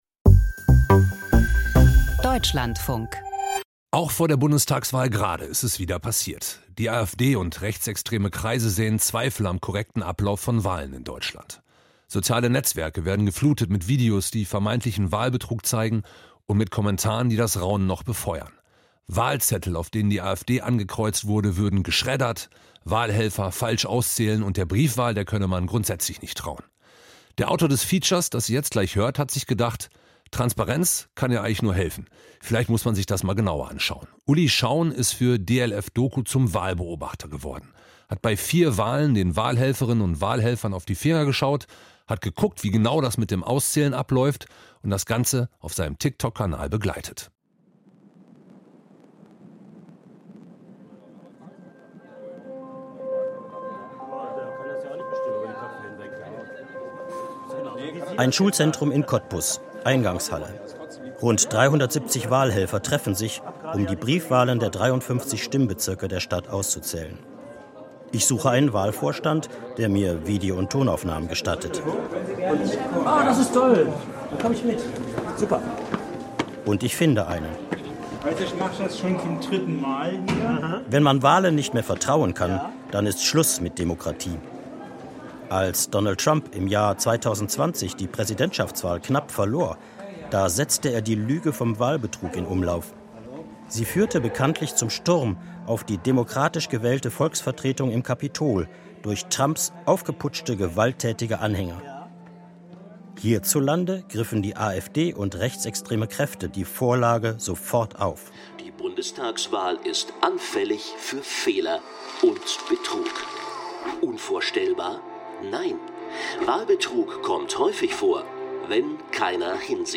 Feature